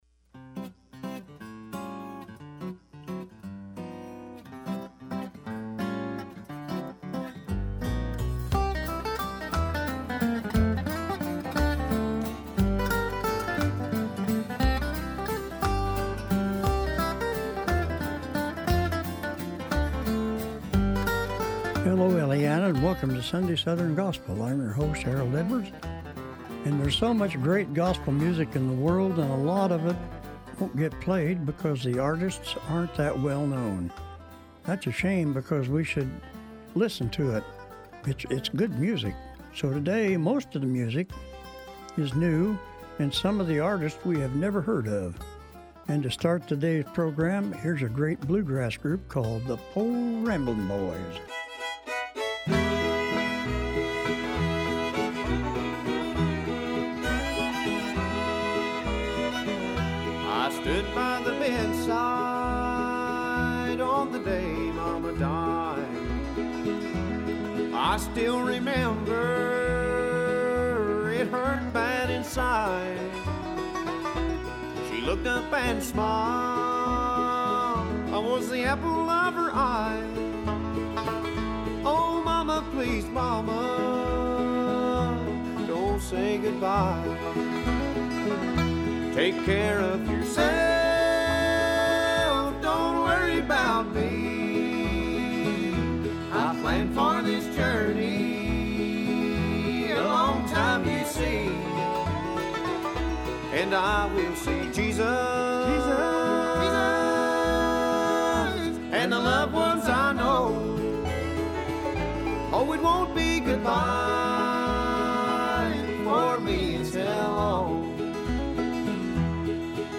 sunday-southern-gosspel-1-25-26.mp3